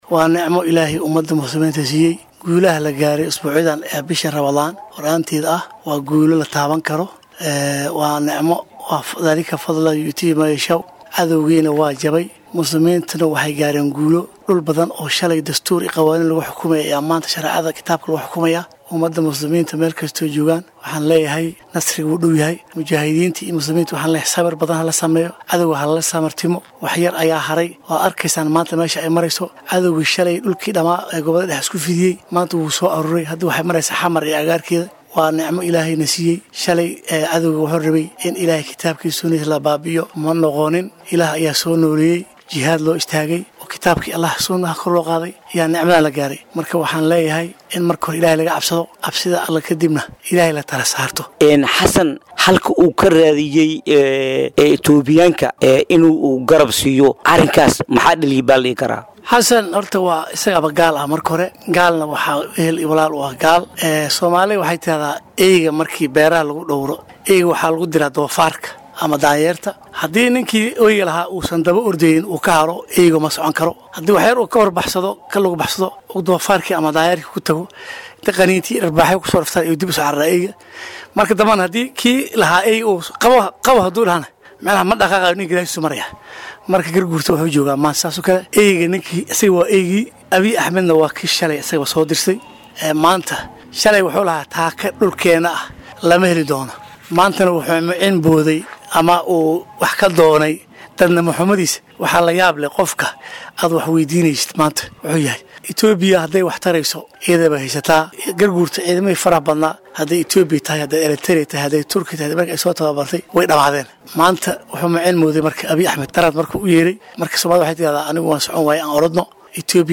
Hogaanka Dhaqanka ee Jubada-dhexe oo Bogaadiyay Guulaha ay Xaqiijisay Al-Shabaab.[WAREYSI]